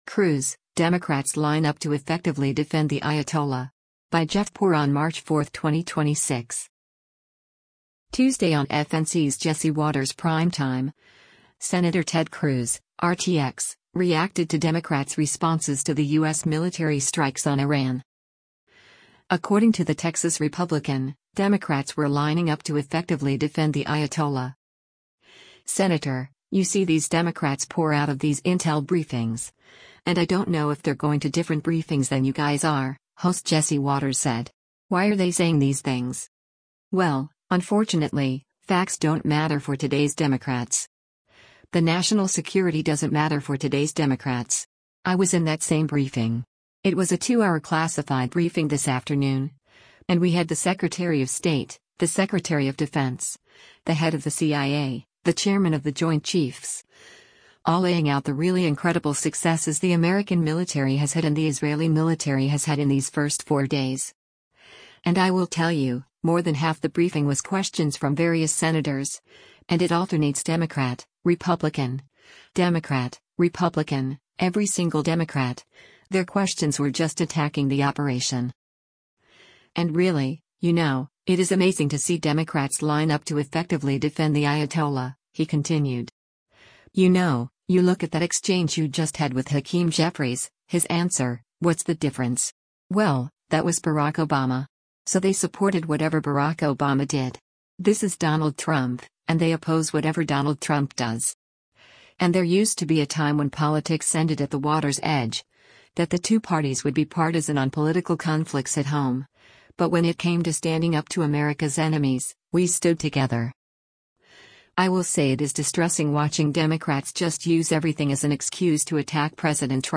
Tuesday on FNC’s “Jesse Watters Primetime,” Sen. Ted Cruz (R-TX) reacted to Democrats’ responses to the U.S. military strikes on Iran.